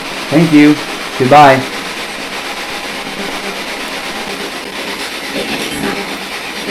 Spirit Box Clip 4
About the clip: Yet another great response that came through our P-SB11 spirit box in the basement "bad area"!
Remember: Listen through headphones, preferably on a PC, to ensure hearing these clips clearly! CD Audio Quality I hear a male voice say